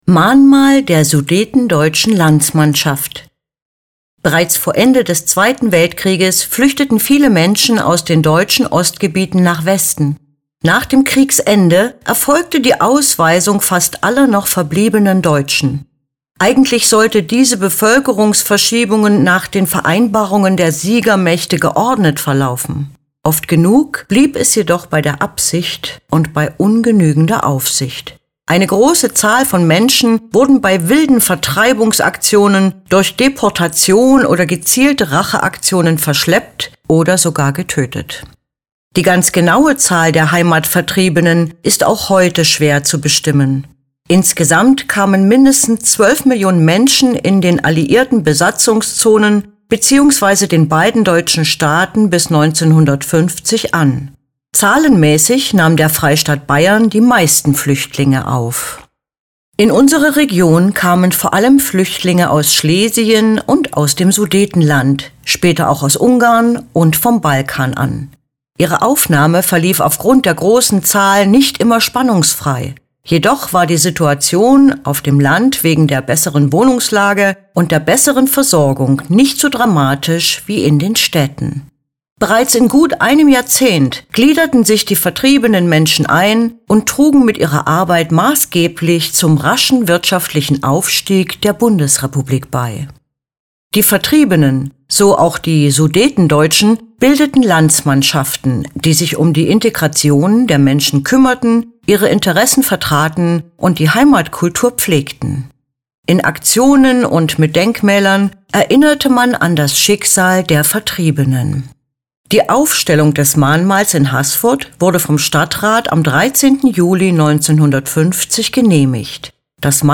Lassen Sie sich die Infos zu diesem Werk einfach vorlesen.